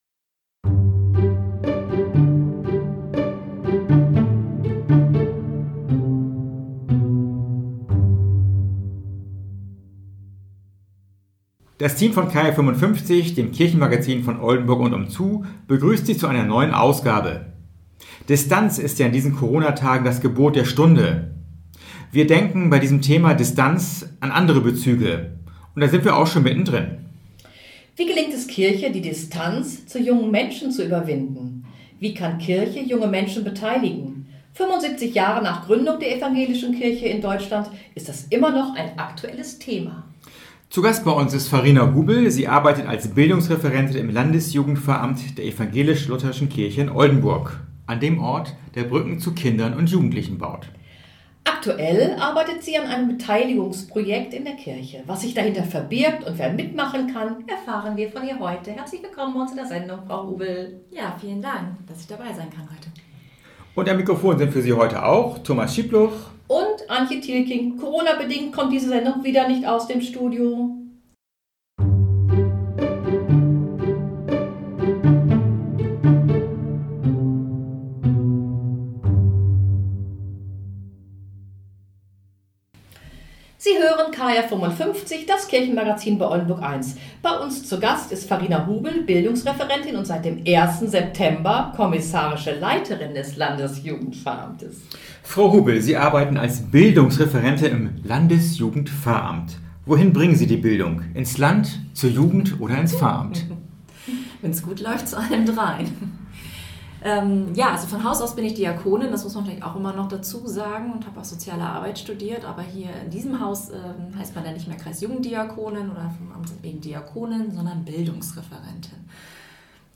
Geistlicher Snack